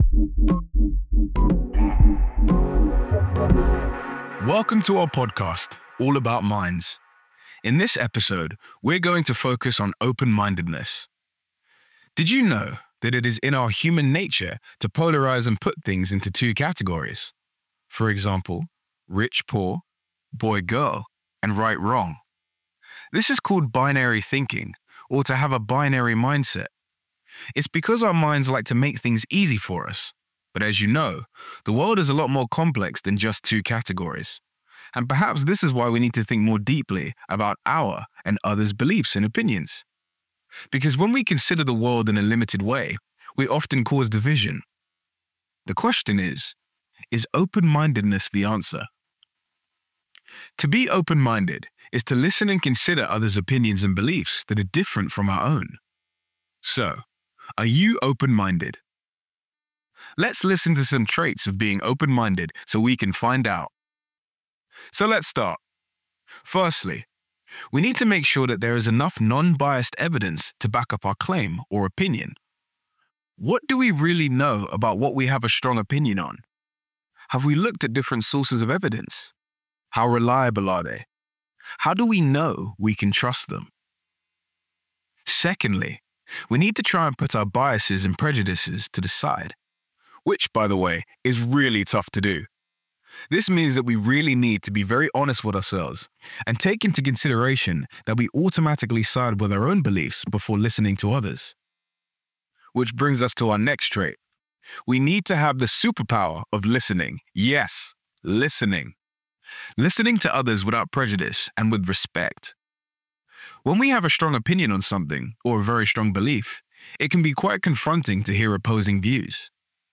C1_Monologue_Open-mindedness_v1.mp3